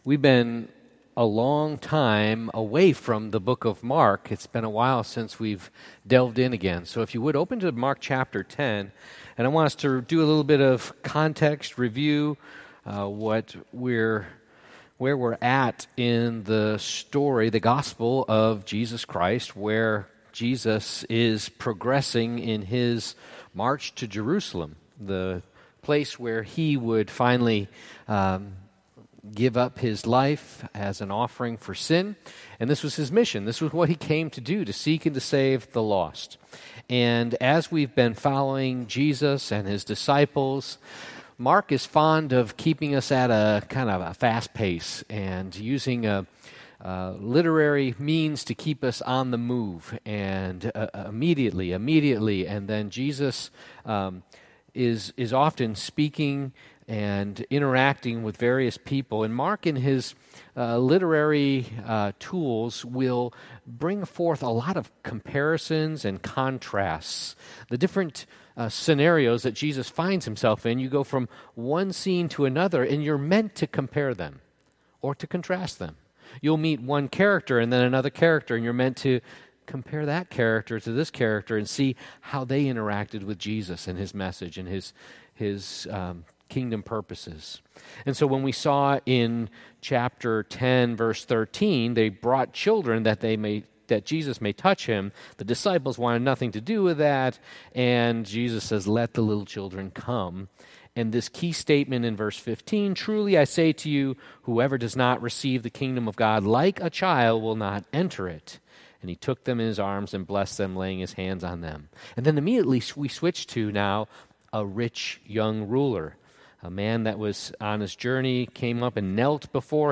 Passage: Mark 10:45-52 Service Type: Sunday Evening Service